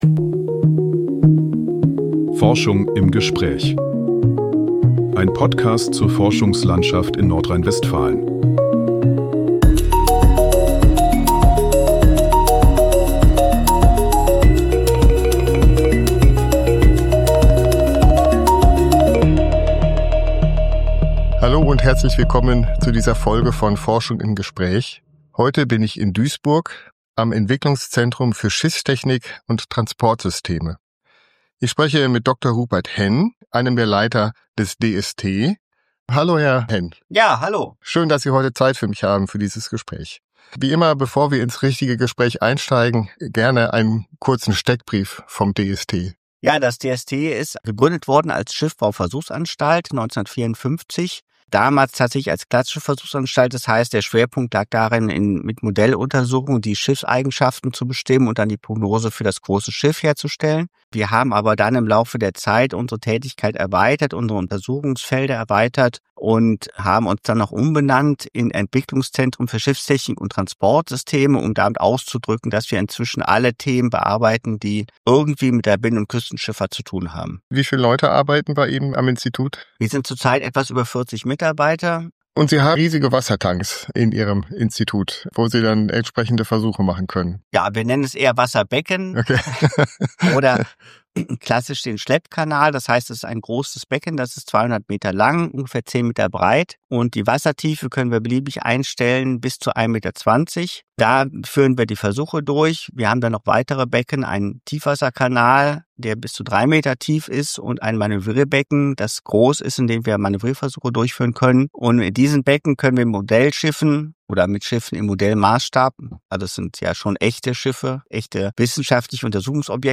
Forschung im Gespräch ist ein Podcast des Wissenschaftsforums im Rheinland e.V. (WiR).